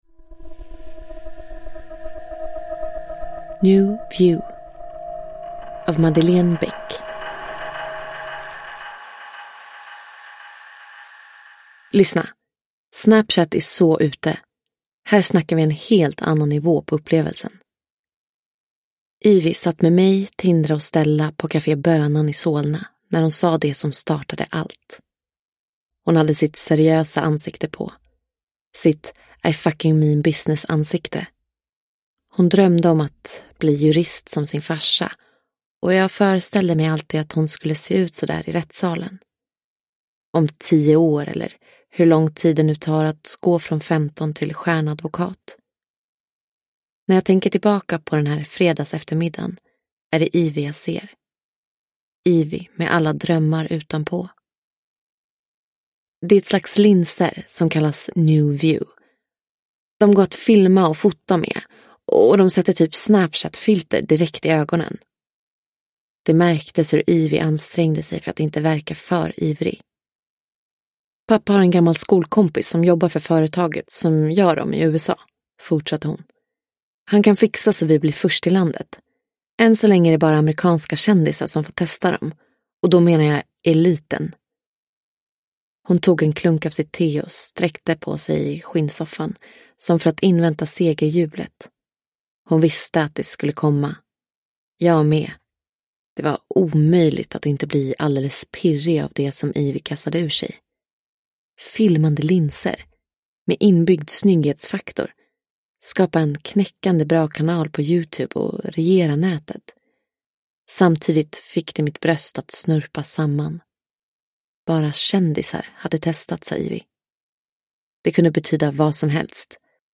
New View – Ljudbok